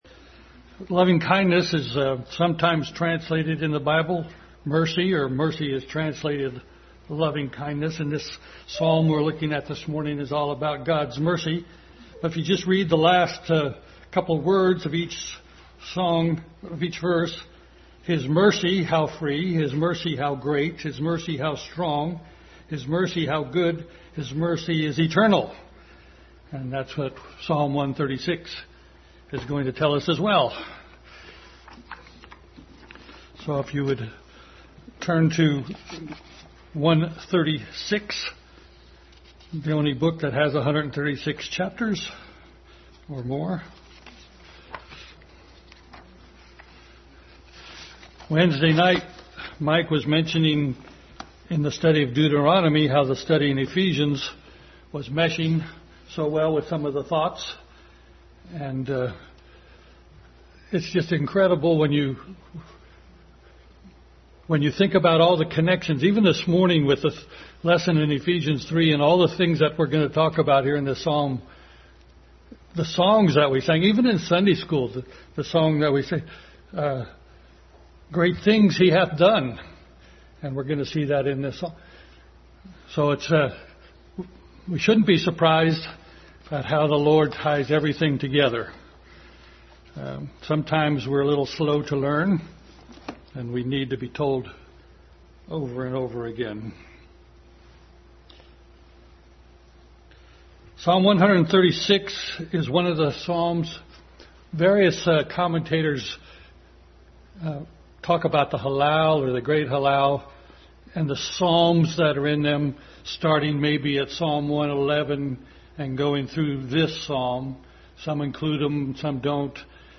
Family Bible Hour message.
Psalm 136 Passage: Psalm 136 Service Type: Family Bible Hour Family Bible Hour message.